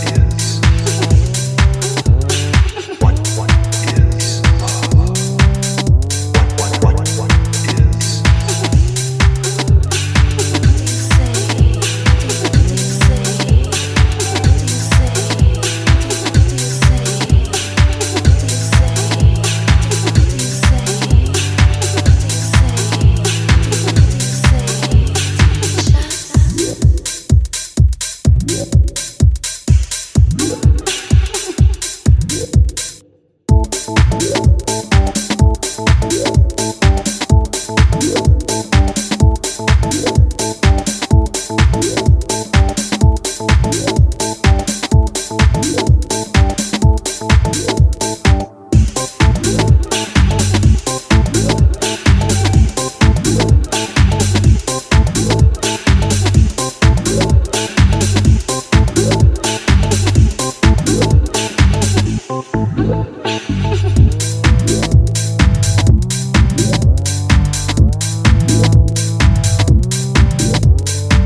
up-beat crowd pleaser productions